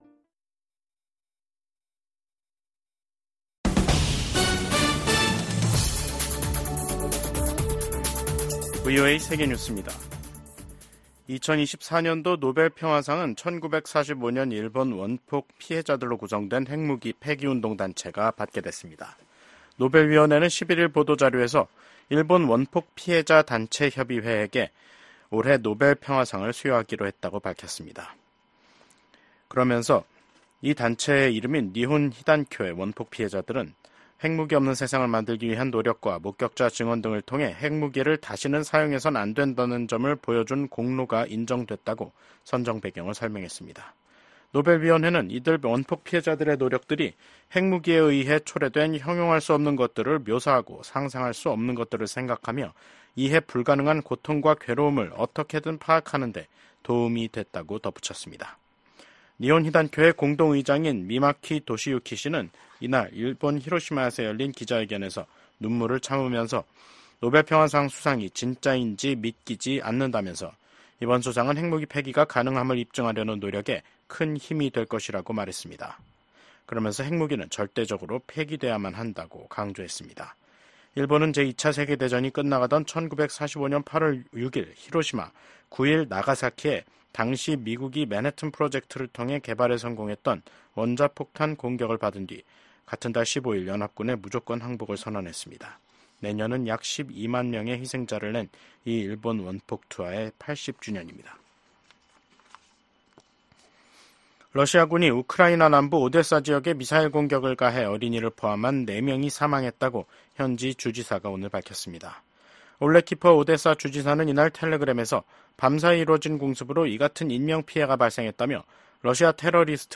VOA 한국어 간판 뉴스 프로그램 '뉴스 투데이', 2024년 10월 11일 2부 방송입니다. 한국 작가로는 최초로 소설가 한강 씨가 노벨문학상 수상자로 선정됐습니다. 윤석열 한국 대통령은 동아시아 정상회의에서 북한과 러시아의 불법적 군사 협력을 정면으로 비판했습니다.